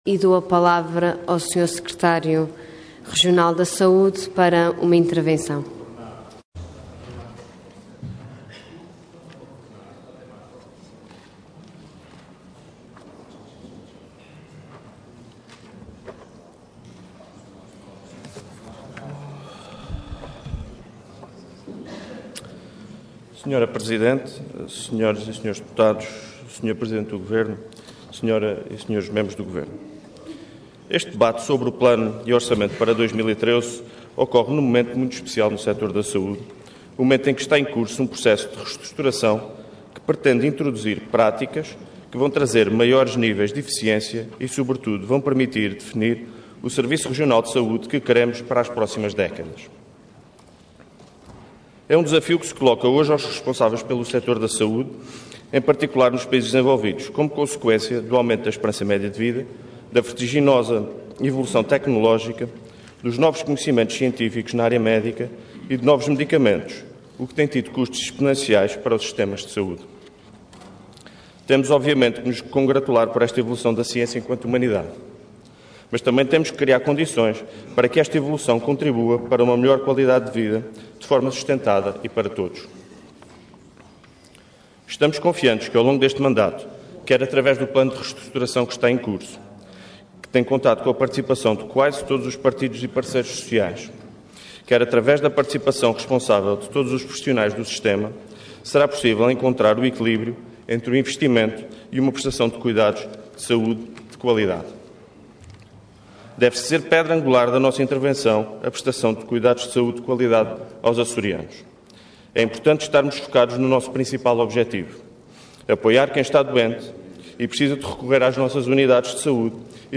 Intervenção Intervenção de Tribuna Orador Piedade Lalanda Cargo Secretária Regional da Solidariedade Social Entidade PS